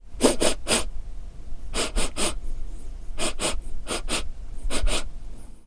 Descarga de Sonidos mp3 Gratis: aspirar 2.
sniff-long.mp3